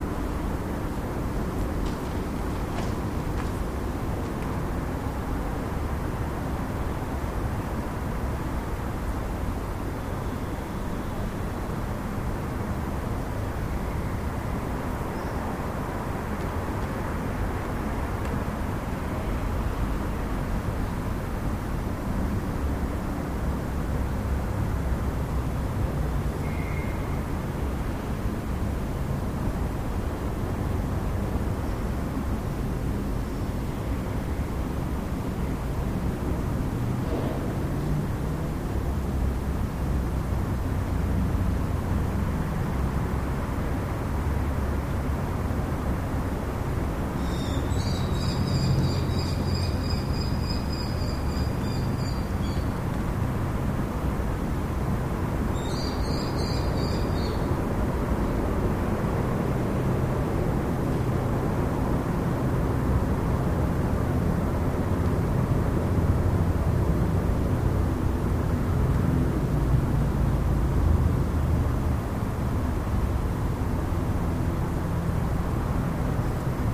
02-luxemburg_centrale_thermique_maschinenhalle_edit.mp3